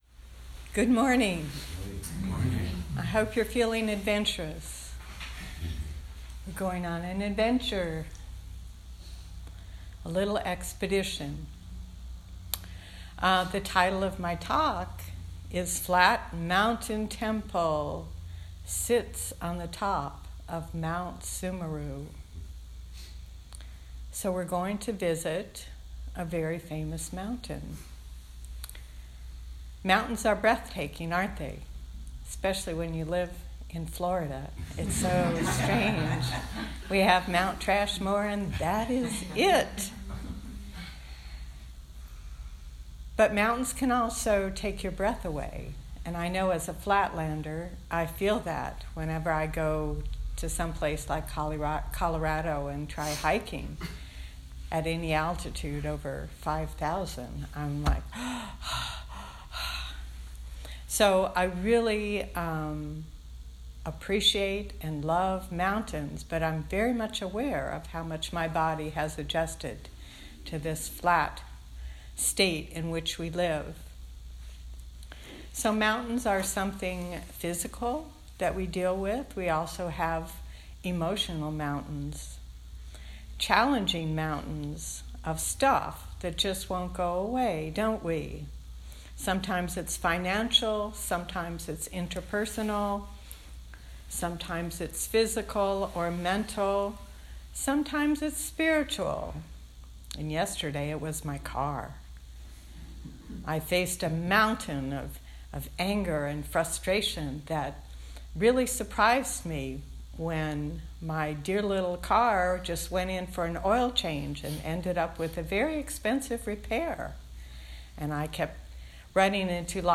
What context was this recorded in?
February 23 2019 Southern Palm Zen Group